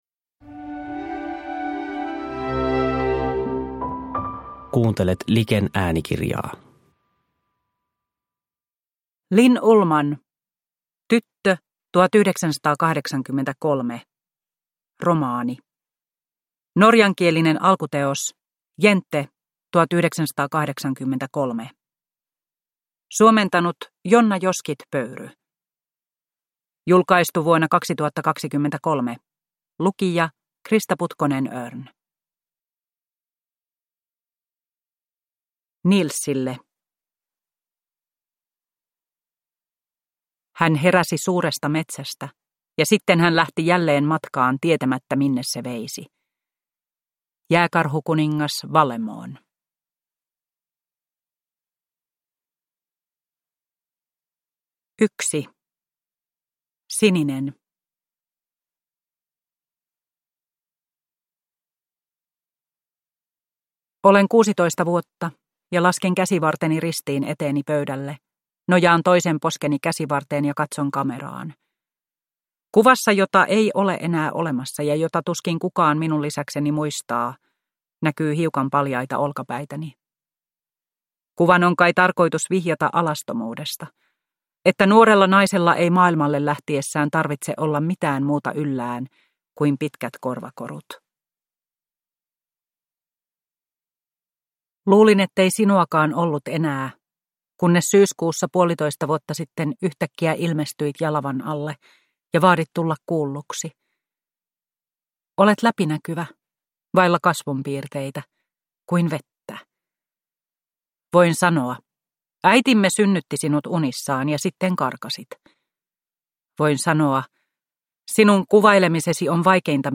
Tyttö, 1983 – Ljudbok – Laddas ner